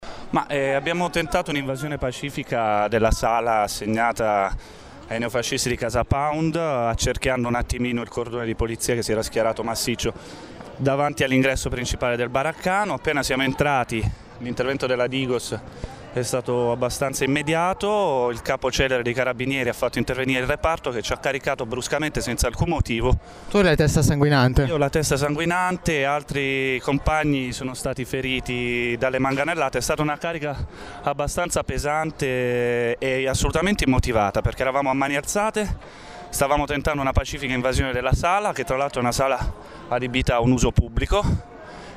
manifestante_ferito.mp3